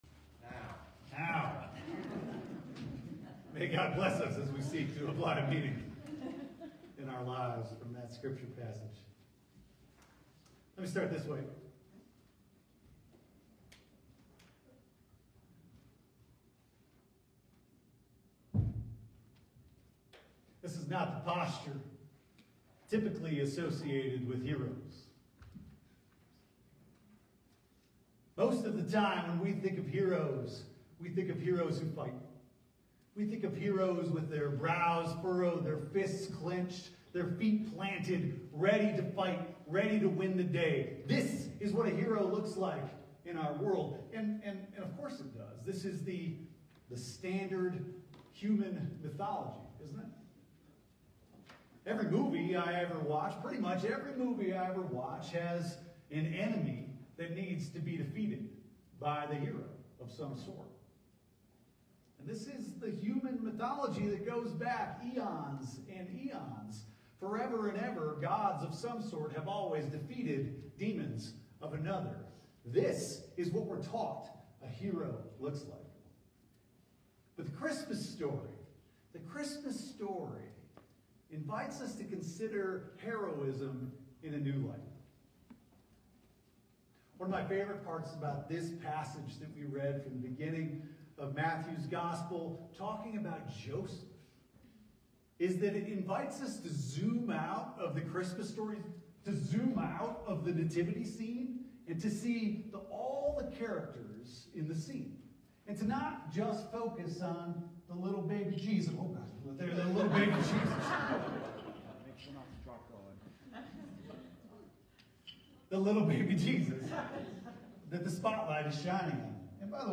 12/21/25 Sermon: Courage (Love) Can't Wait